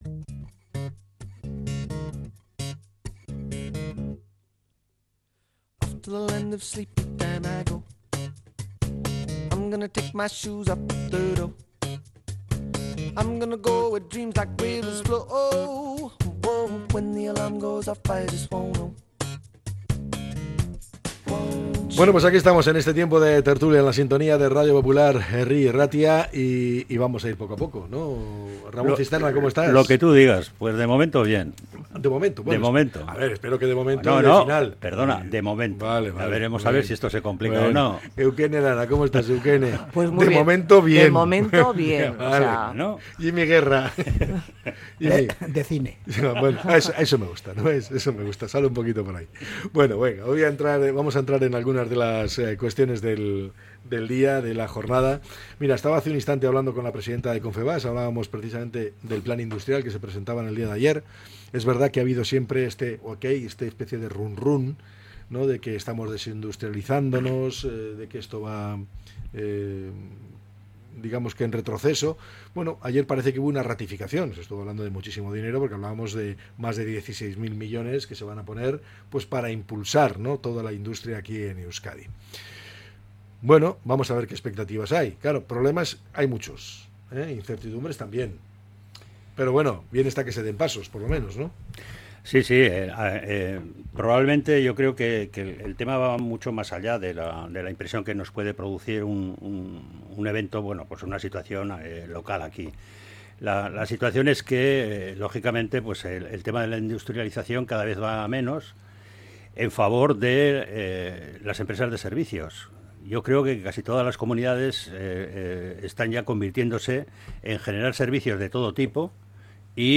La tertulia 12-06-25.